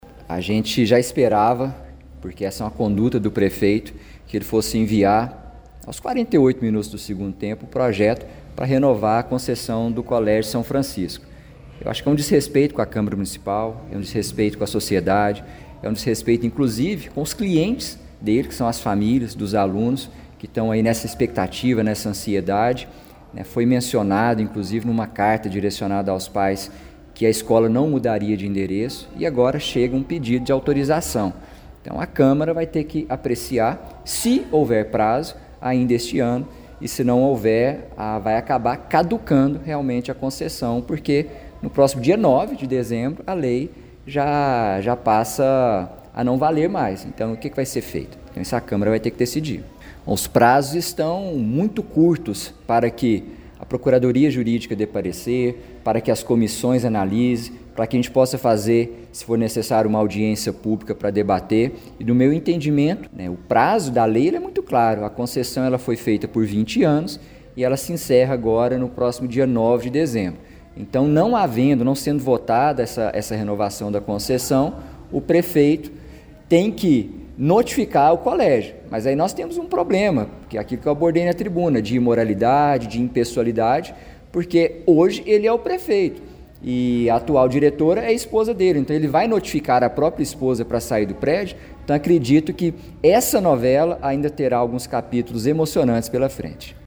O Portal GRNEWS acompanhou a reunião ordinária da Câmara Municipal de Pará de Minas nesta terça-feira, 03 de dezembro.